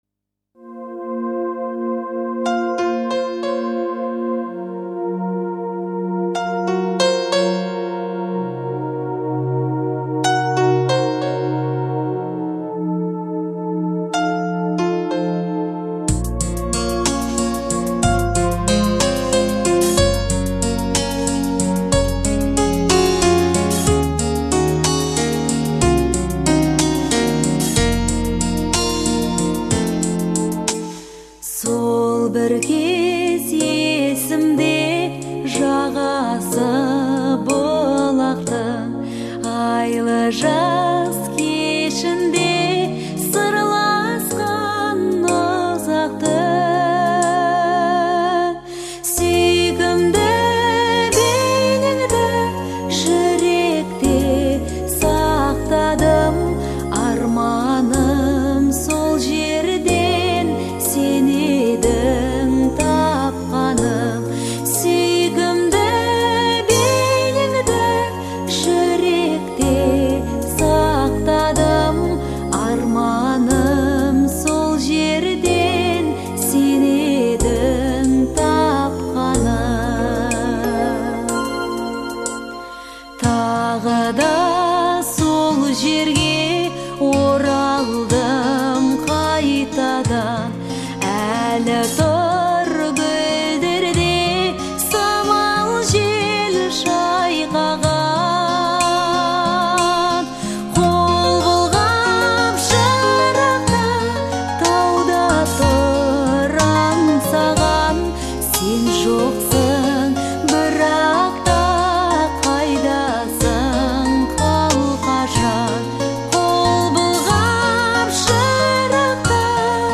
а мелодия легко запоминается.